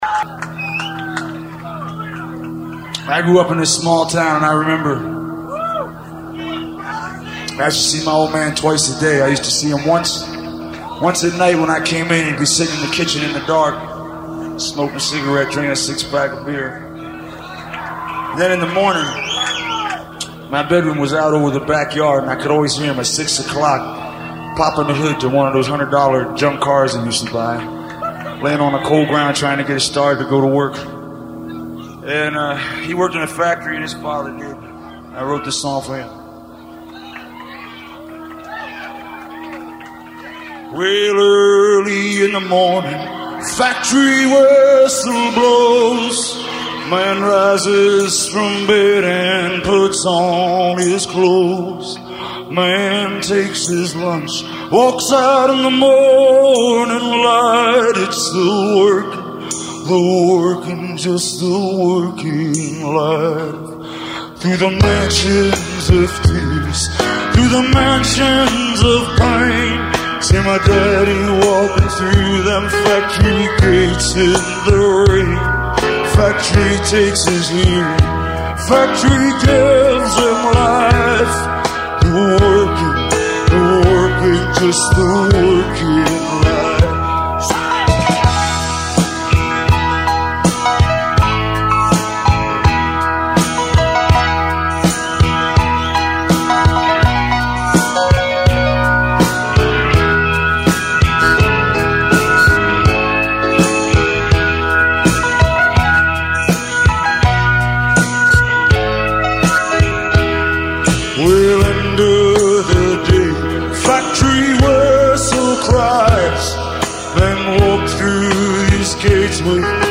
organ
saxophone